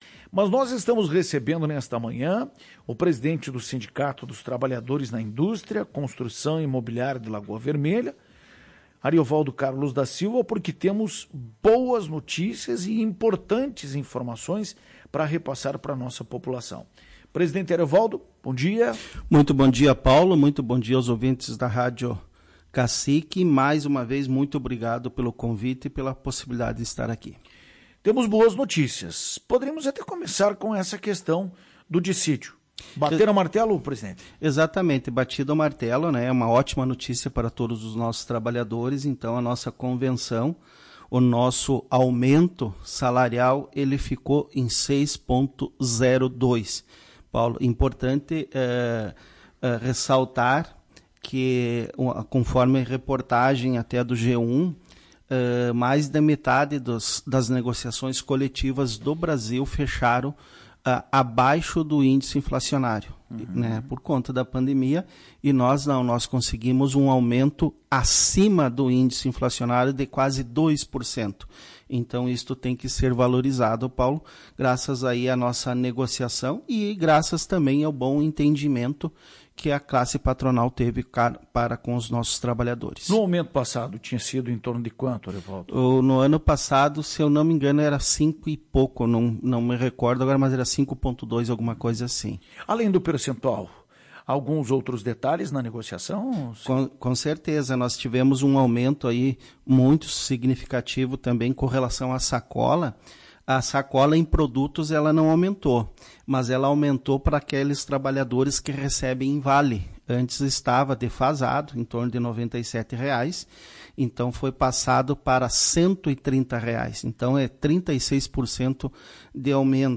em entrevista à Tua Rádio